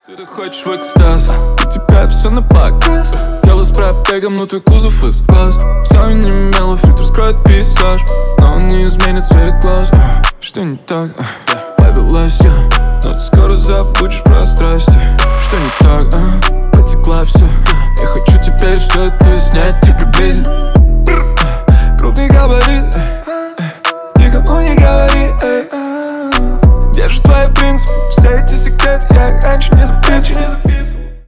Рэп и Хип Хоп